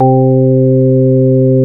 MELLOW LO.wav